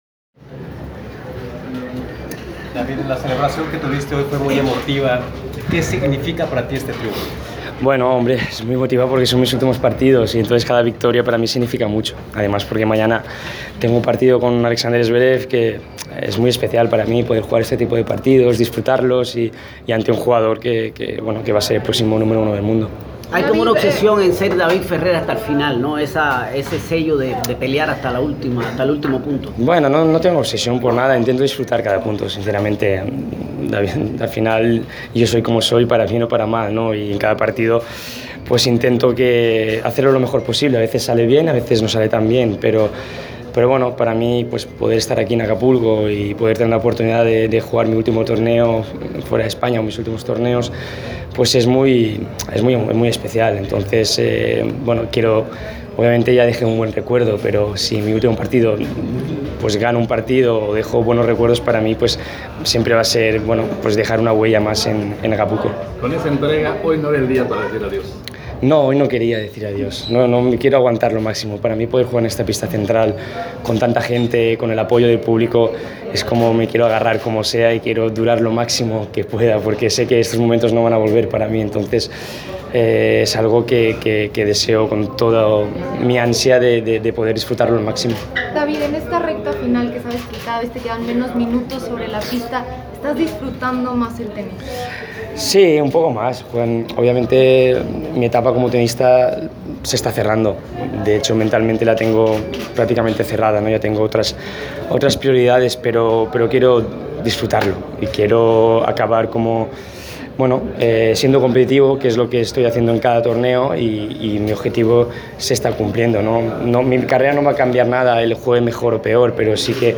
Press Conference – David Ferrer (27/02/2019)